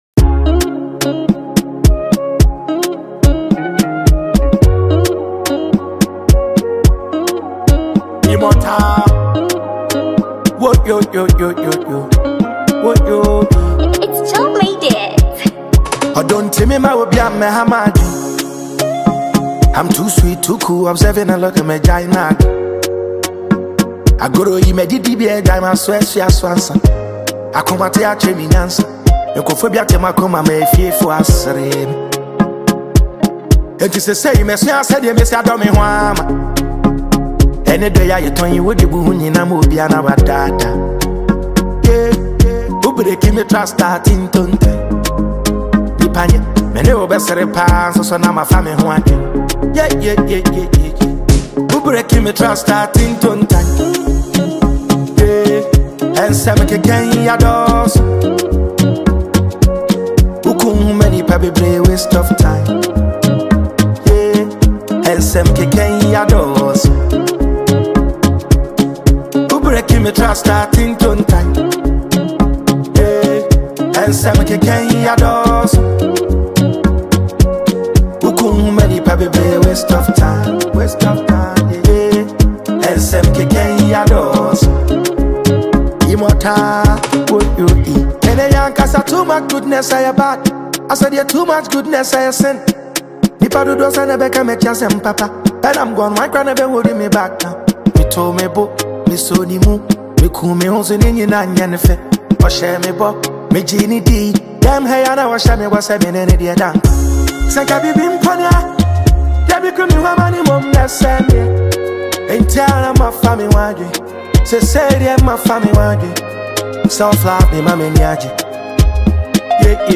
Highly-rated Ghanaian rapper